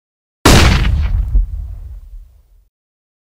flashbang_cs_go_sound_effect.mp3